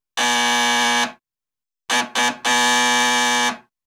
Stair_Horror/DoorBuzzer1Electri PE250301.wav at master